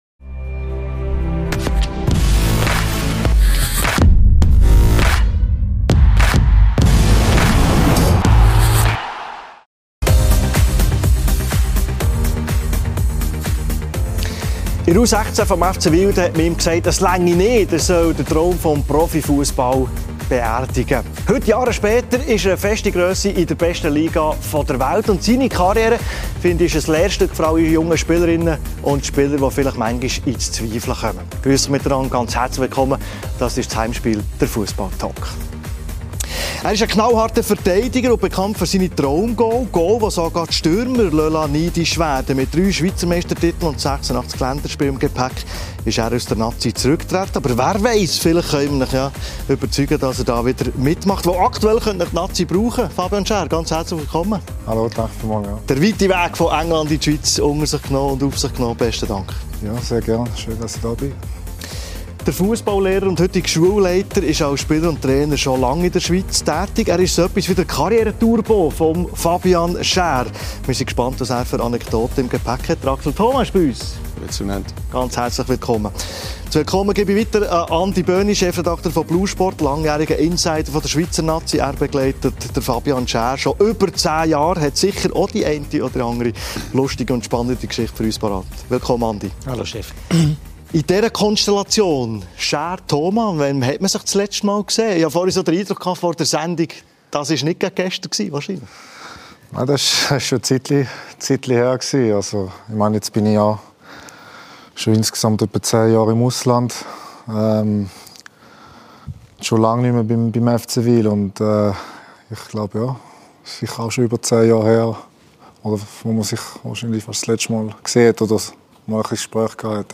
Fabian Schär über schwierige Momente, eine mögliche FCB-Rückkehr und seinen Nati-Rücktritt ~ Heimspiel ⎥ Der Fussball-Talk Podcast